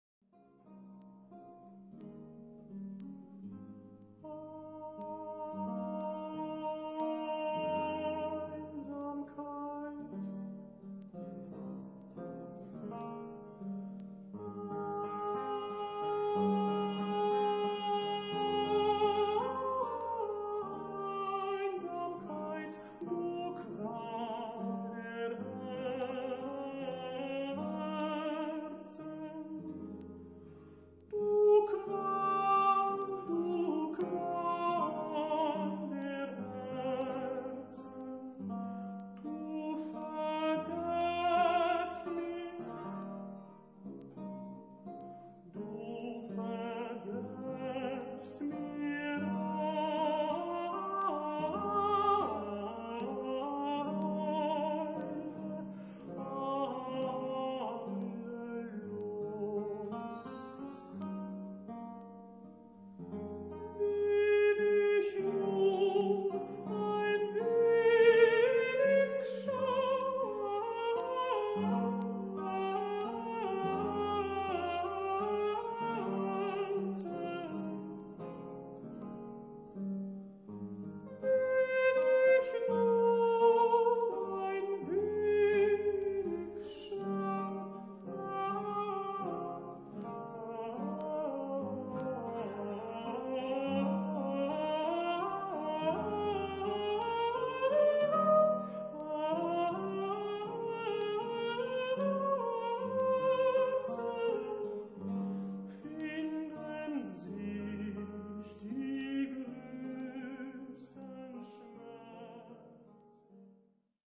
Melancholy, slow, sweet